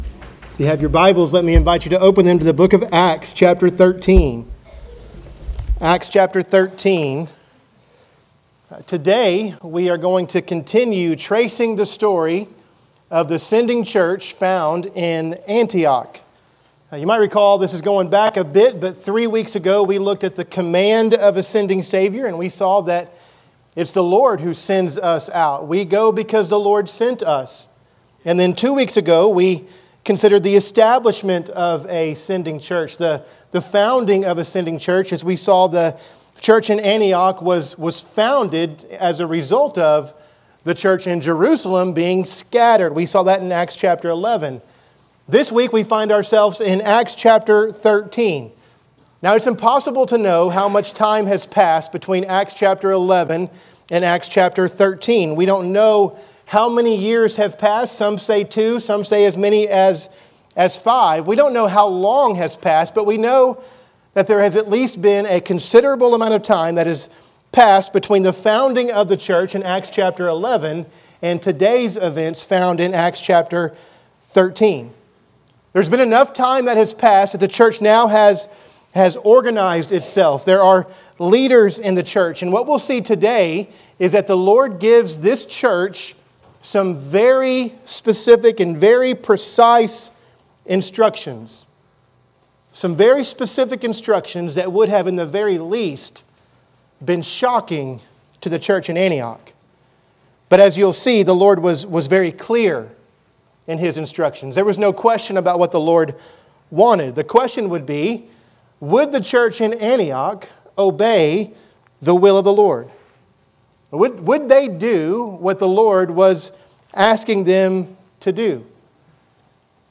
This sermon continues to trace the story of the Antioch Church as a sending church. Open your Bibles to Acts 13:1-3 and follow along!